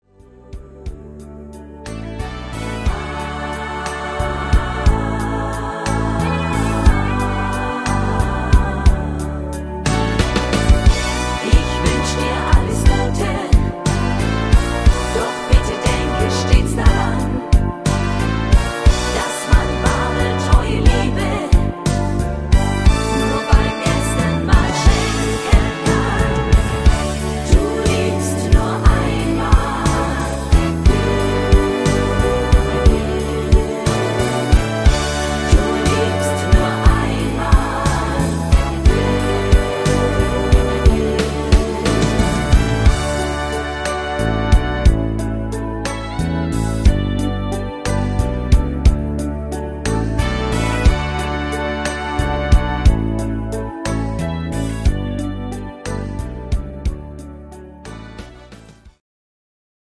Rhythmus  Slowrock
Art  Deutsch, Schlager 70er